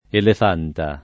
Elefanta [ elef # nta ]